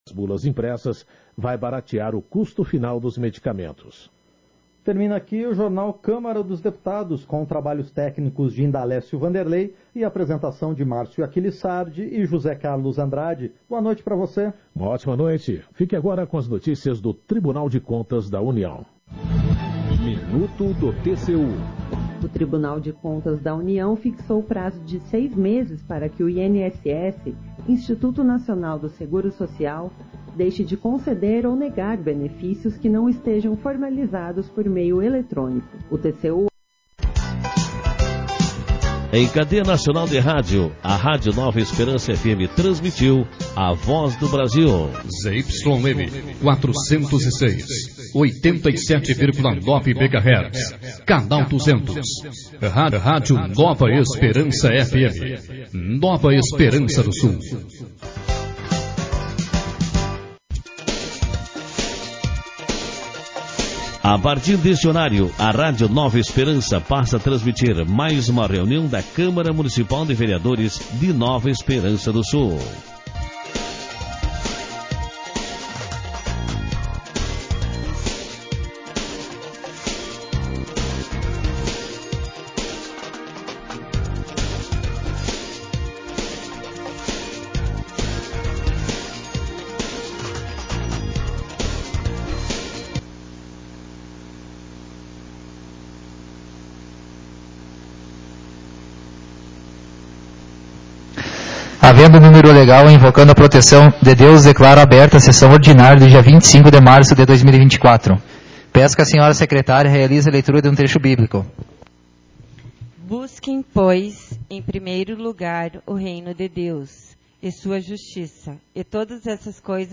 Sessão Ordinária 08/2024